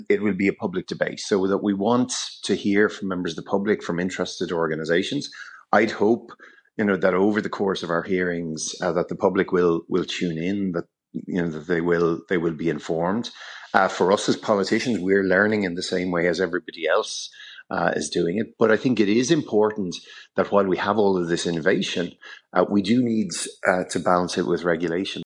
Committee Chair Malcolm Byrne says the Committee won’t be a talking shop: